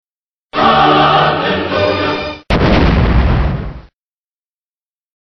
worms_–holy_hand_grenade.mp3